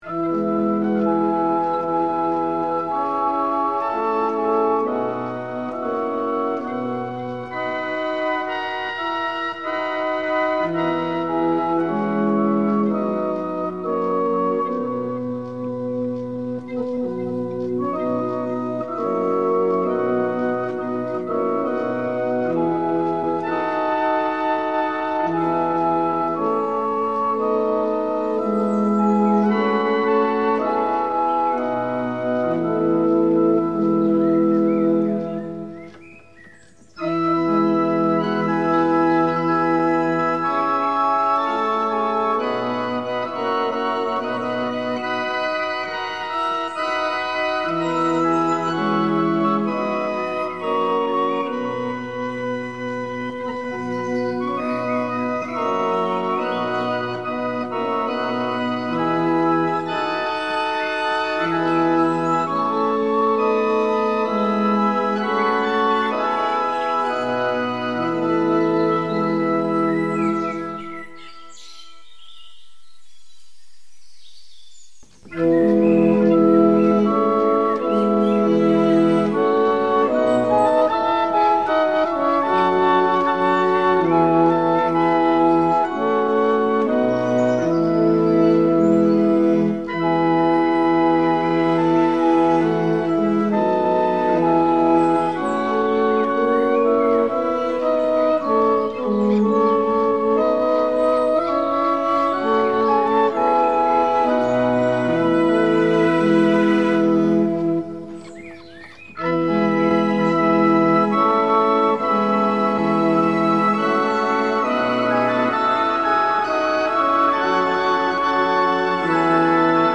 barrel organs
The three barrel organs being played here were built between 1764 and 1850
After a number of abandoned approaches, rather than “reinterpret” the sounds, I have built a world around them, orchestrating with strings and brass, and blending sounds both natural and unnatural into the mix aiming to give the finished track a widescreen feel.